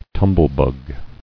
[tum·ble·bug]